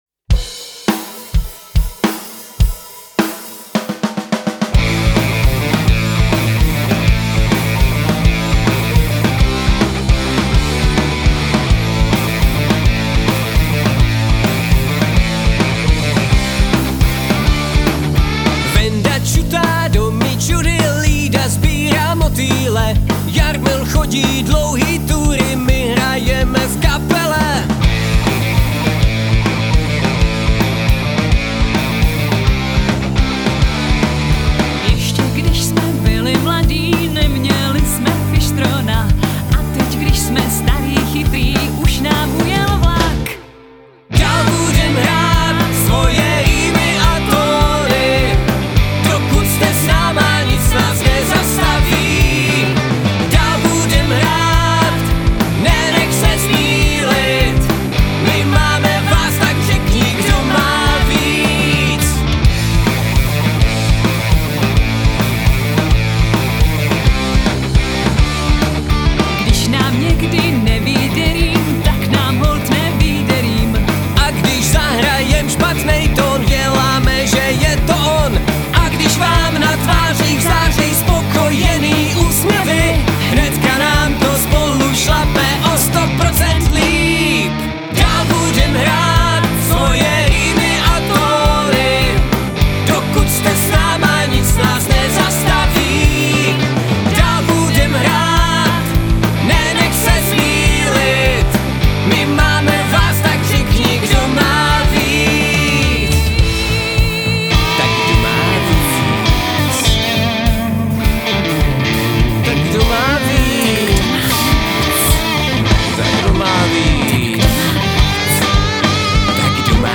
Máme první mix jedné za tří písní které jsme natočili.